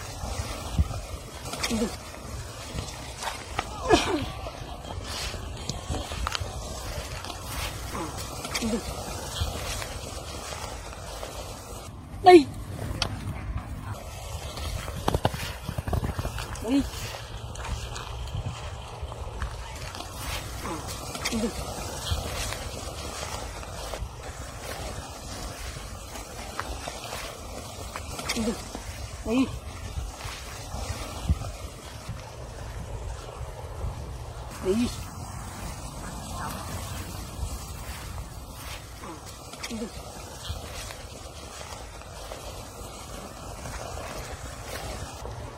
Tiếng Trâu, Bò Cày, bừa… ruộng cạn, gia súc làm đồng
Thể loại: Tiếng động
Description: Âm thanh của tiếng trâu, bò cày lặng lẽ vang vọng trong không gian đồng quê, hòa quyện với tiếng bừa lạo xạo trên ruộng cạn, tạo nên một bản giao hưởng sống động của mùa màng.
tieng-trau-bo-cay-bua-ruong-can-gia-suc-lam-dong-www_tiengdong_com.mp3